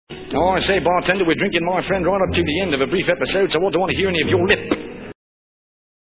Bobby also demonstrated his versatility in this hour with his singing, impressions, acting and playing the guitar and piano.